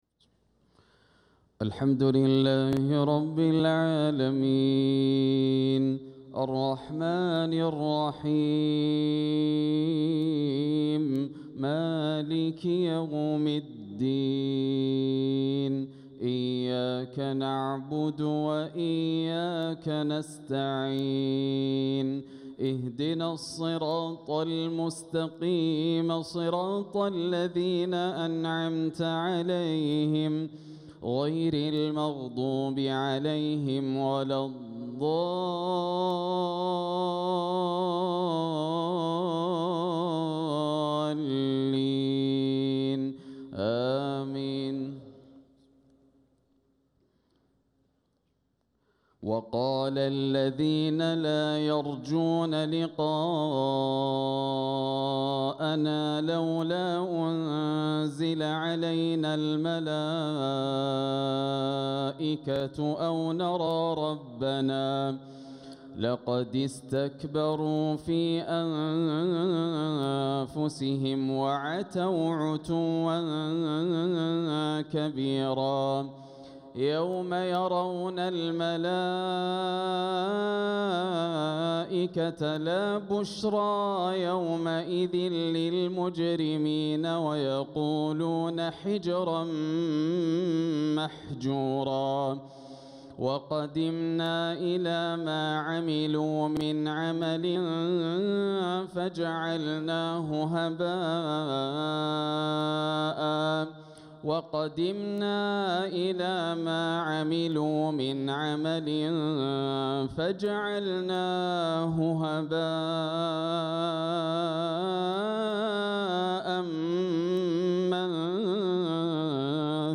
ترتيل خاشع مزدان من سورة الفرقان | عشاء 22 ذو الحجة 1446هـ > عام 1446 > الفروض - تلاوات ياسر الدوسري